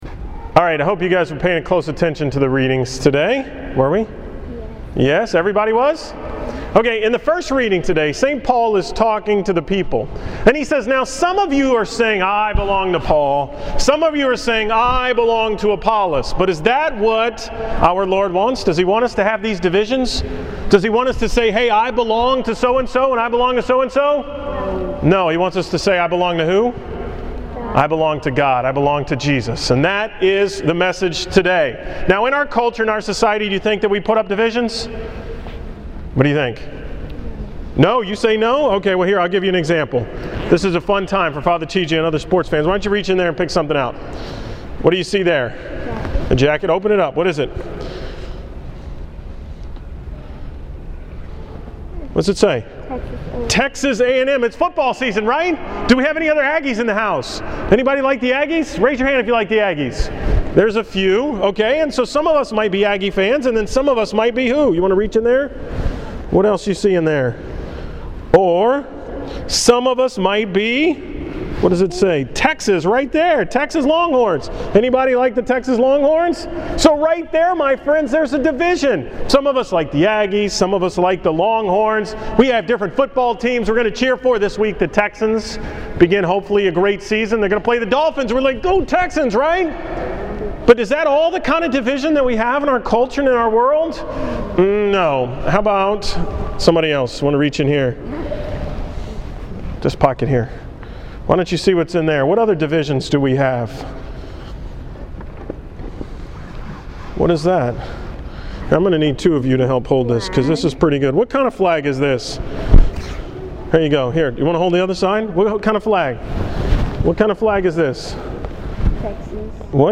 From the school Mass on September 5, 2012
Category: School Mass homilies